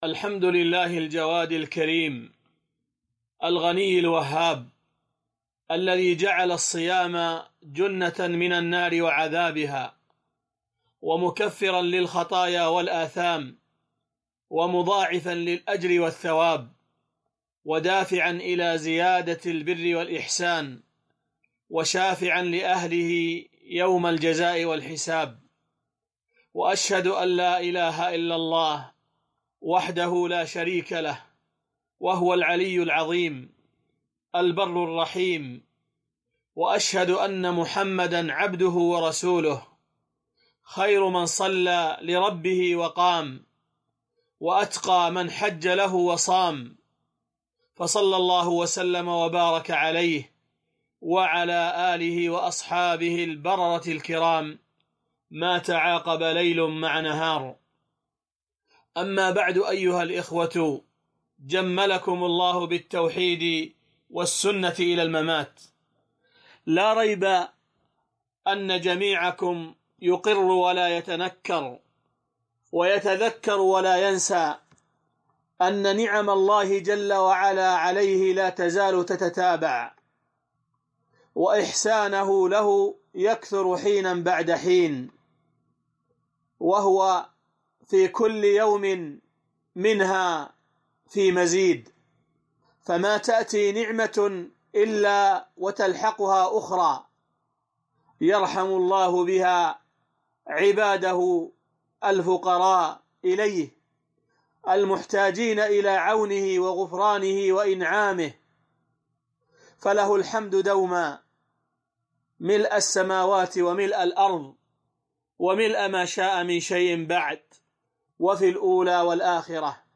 محاضرة - ها قد أقبل شهر رمضان فأحسنوا فيه - دروس الكويت